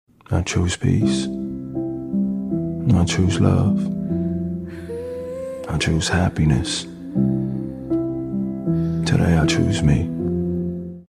Cattle farm